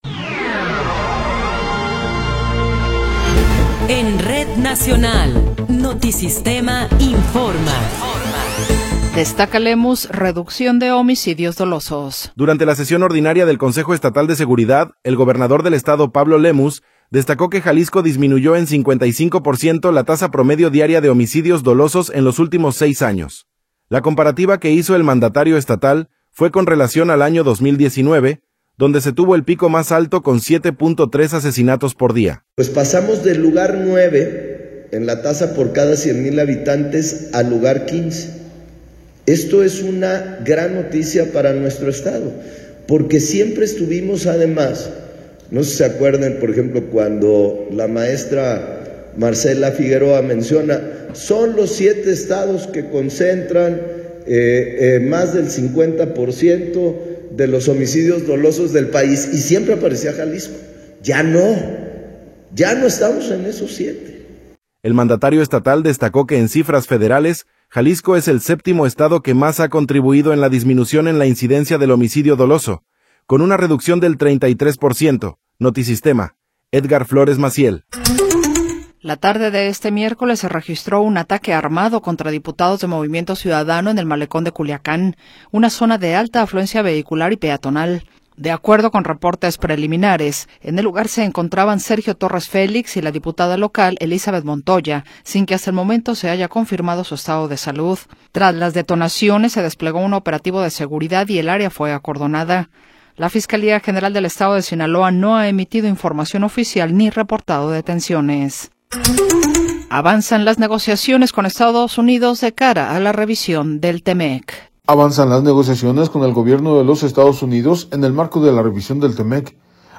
Noticiero 16 hrs. – 28 de Enero de 2026
Resumen informativo Notisistema, la mejor y más completa información cada hora en la hora.